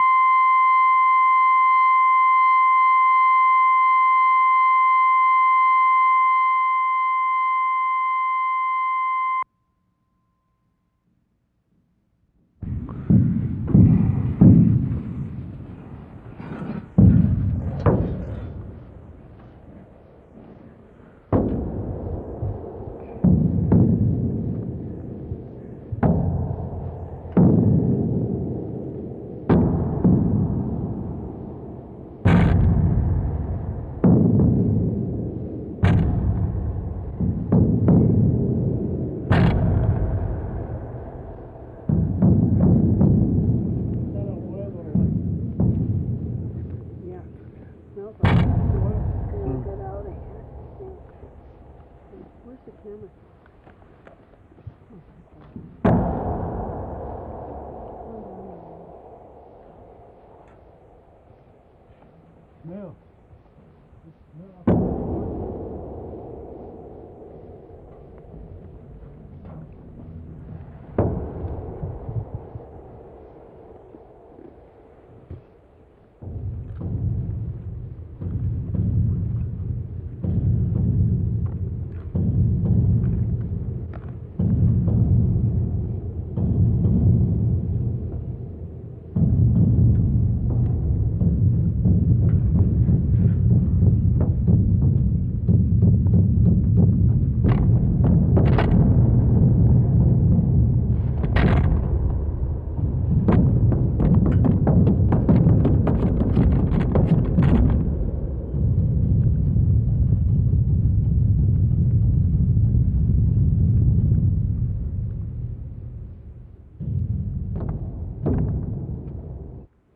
PERCUSSIVE SOUNDS, 3:30
2.  Persistent low-pitched knocking sounds, such as on very large metal containers, long decays